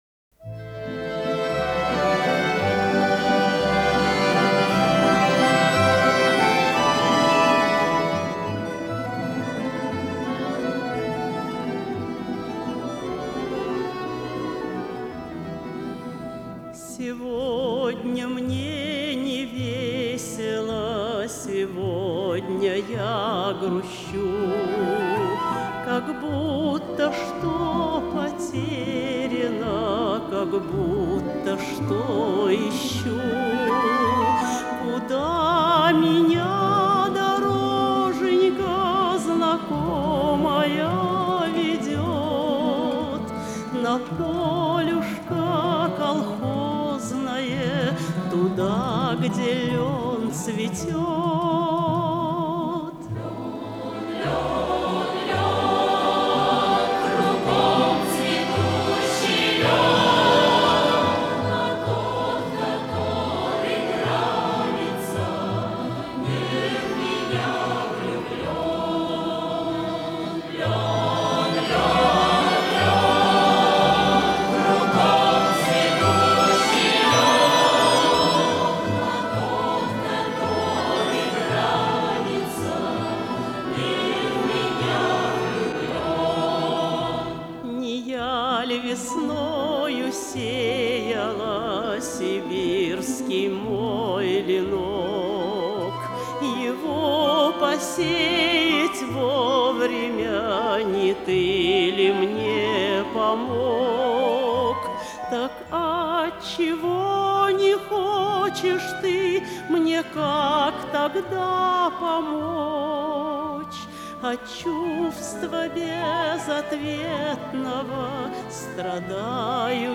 пение